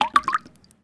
Huawei Bildirim Sesleri
Drip
Drip.mp3